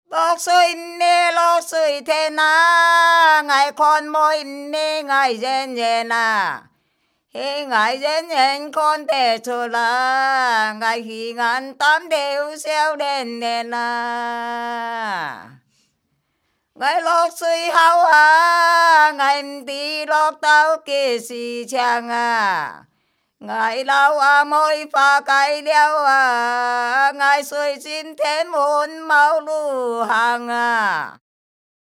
區內一群長者聚在一起，輪流唱著塵封了的古老歌謠，越唱越開懷，不覺間勾勒出昔日農鄉的浮世繪。